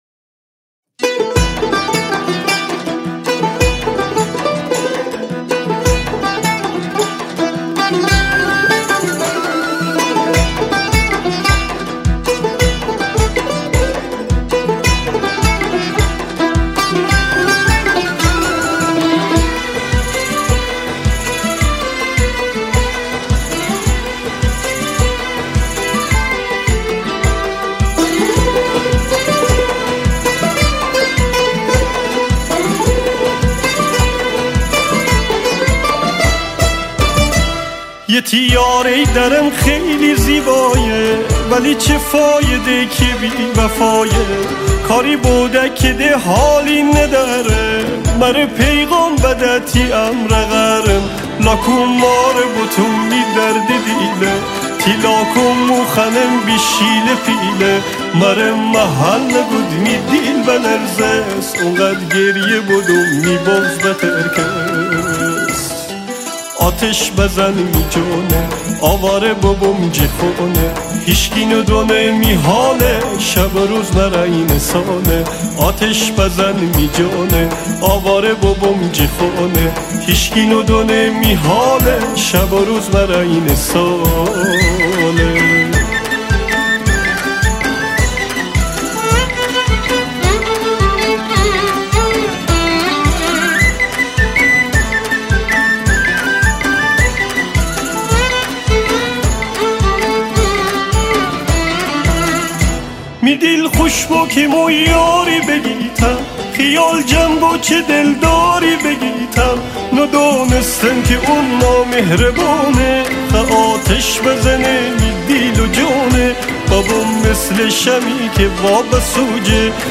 اهنگ گیلانی شاد